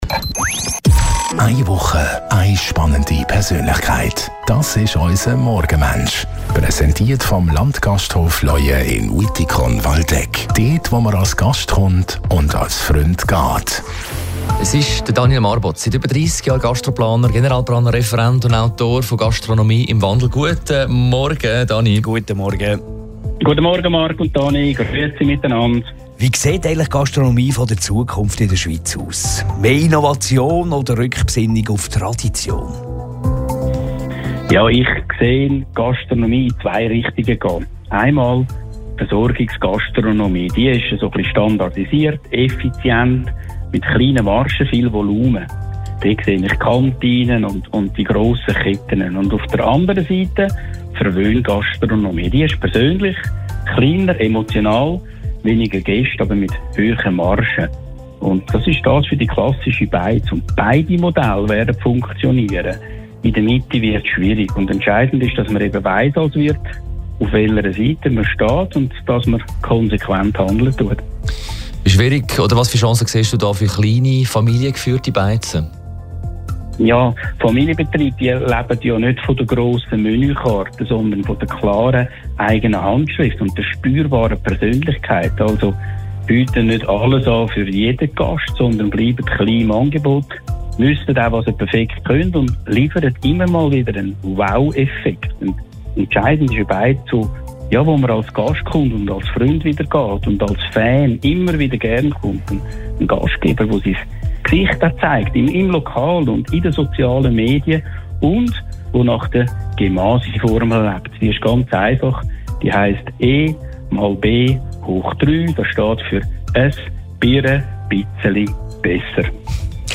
Gespräche zur Zukunft der Gastronomie in der Schweiz